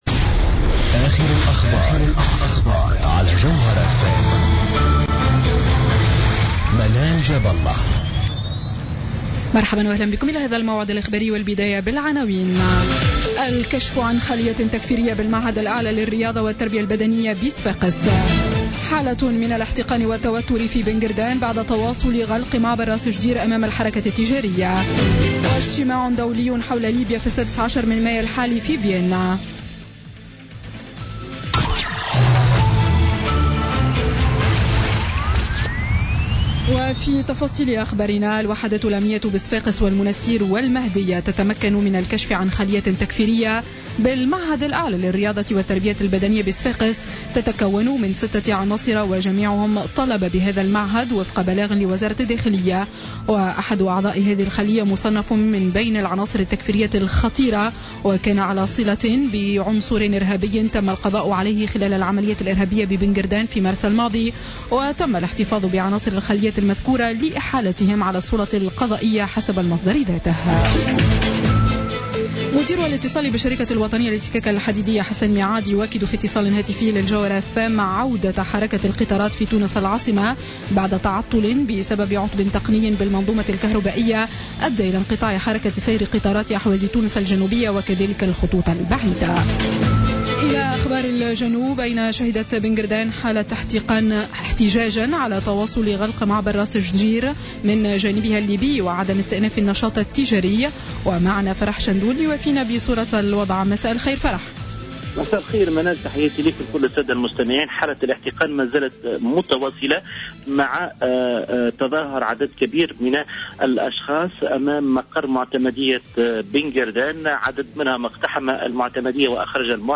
نشرة أخبار السابعة مساء ليوم الاثنين 9 ماي 2016